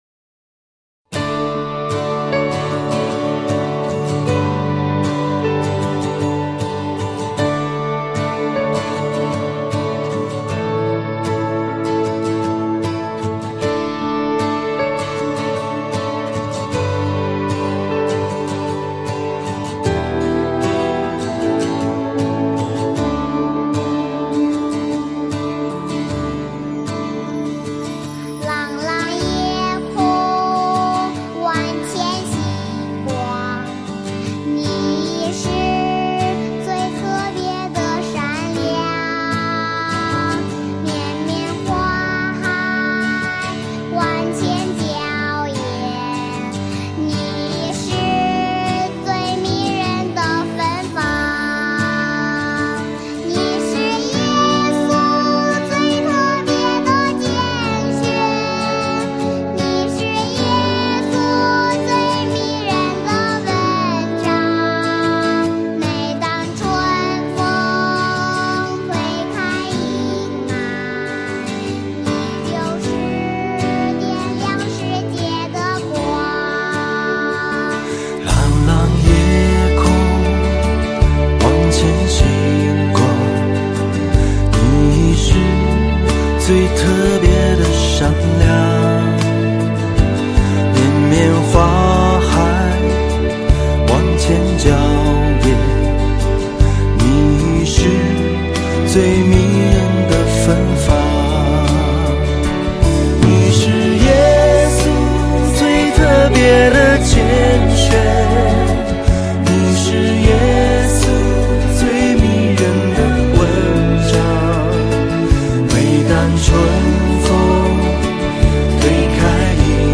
儿童赞美诗 | 你是最特别的拣选